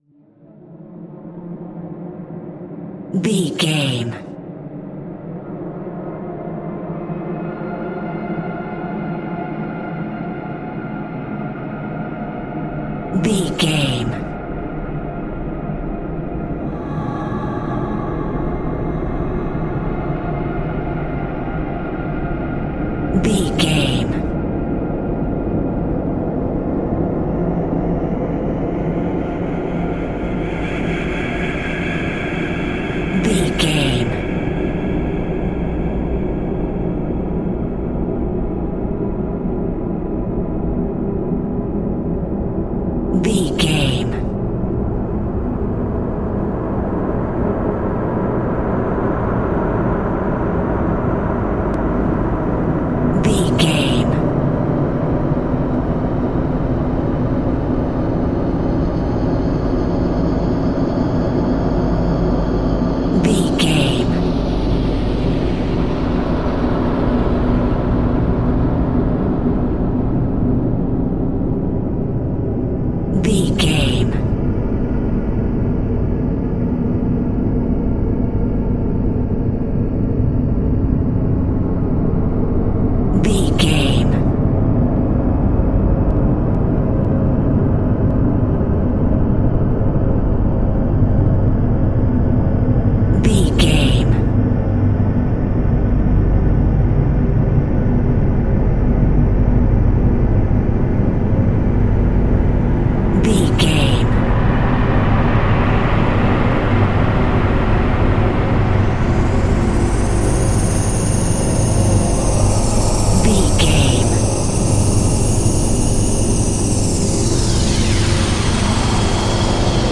Atonal
Slow
scary
tension
ominous
dark
suspense
haunting
eerie
creepy
synth
keyboards
ambience
pads
eletronic